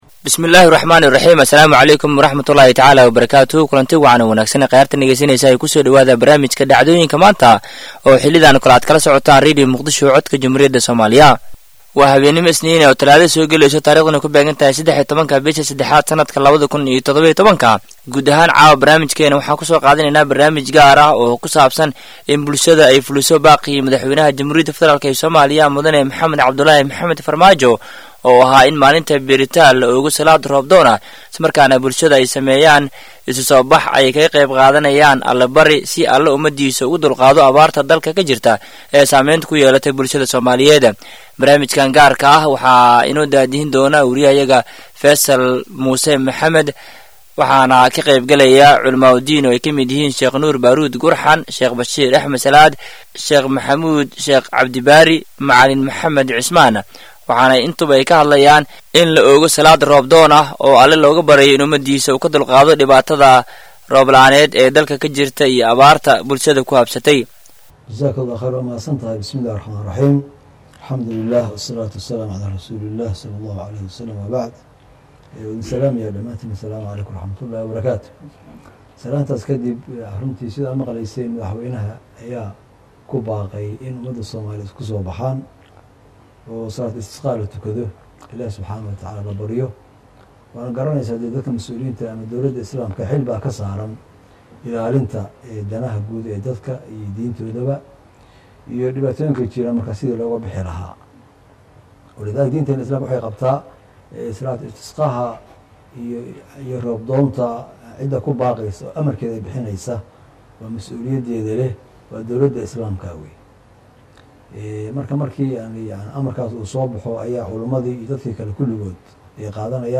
Barnaamij gaar ah oo ku saabsan Abaaraha iyo in la oogo salaad roob doon ah “Dhageyso”